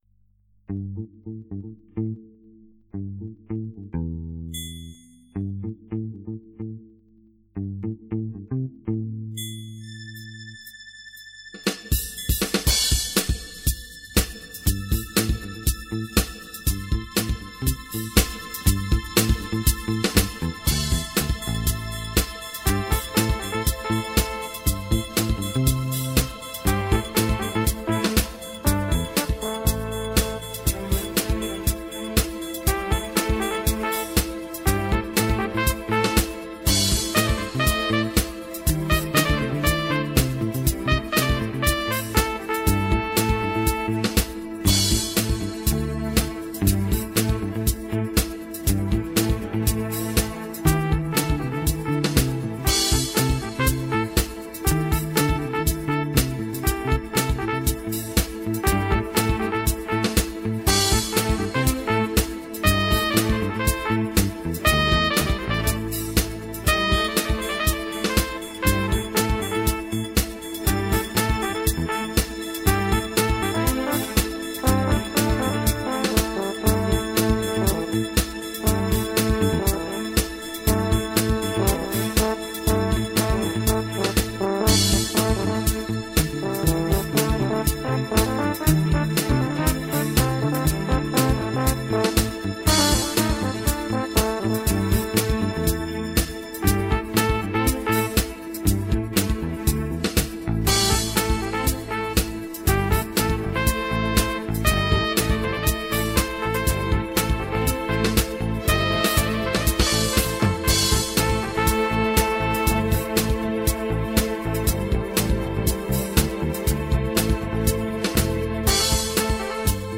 Een  avontuurlijk luister drama gemaakt met een Casio WK 1800
The Nightwalker 2009 Film Track (korte MP3 versie) Heerlen Limburg The Netherlands